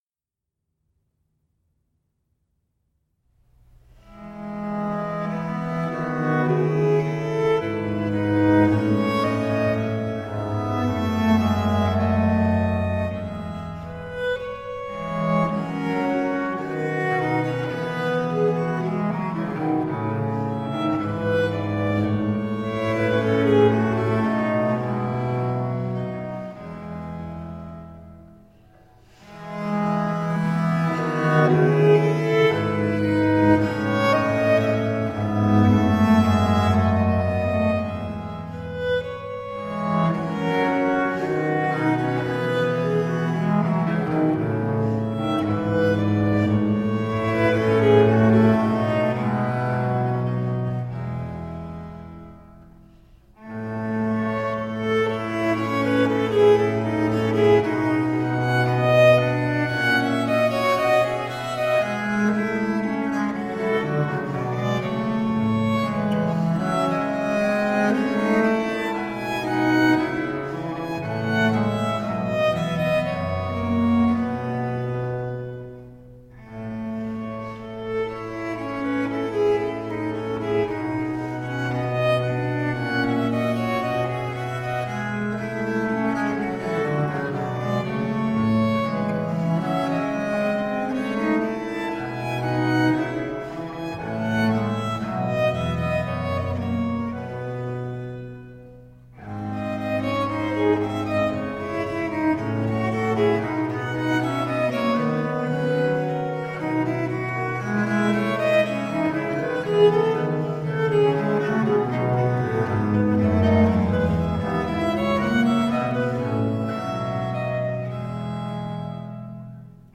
Viola da gamba girls gone wild!!.